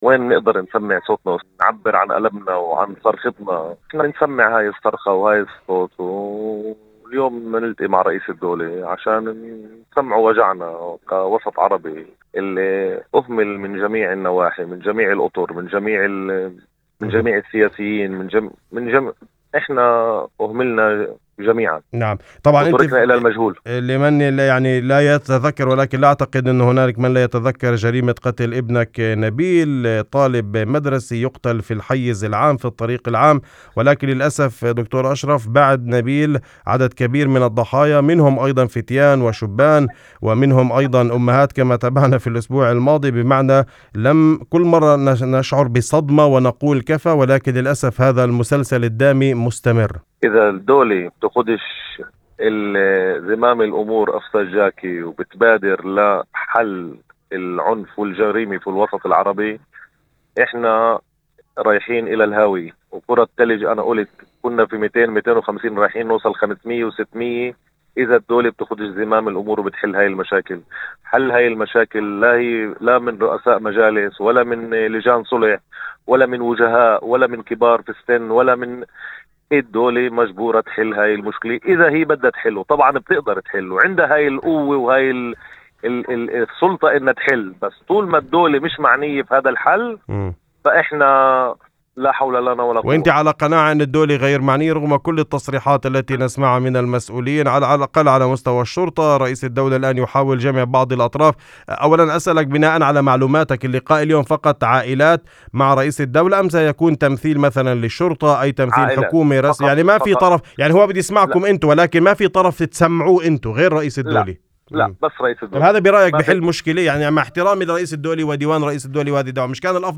وفي هذا السياق كانت لنا مداخلة على إذاعة الشمس ضمن برنامج "أول خبر"